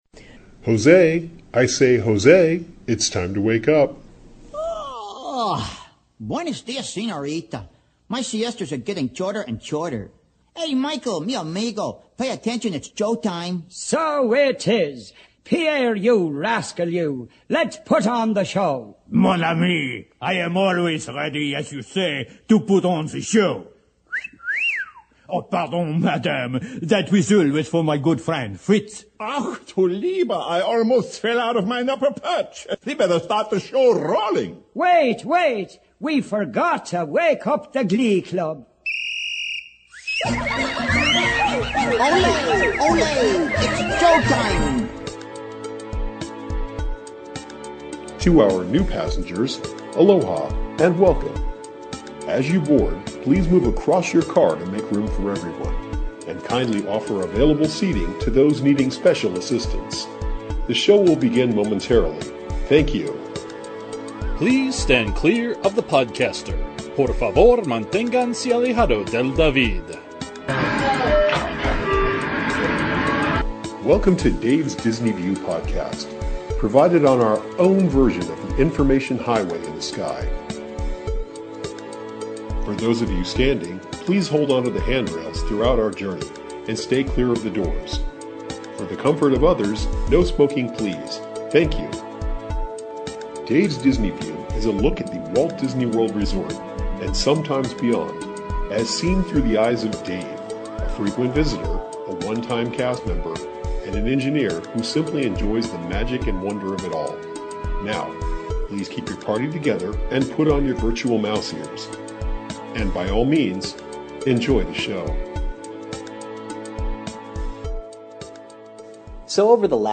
On this episode, I take a ride on SpaceShip Earth. Come along and listen to your host, Judi Densch, tell the tale of communications. And hear my comments on a few things as well.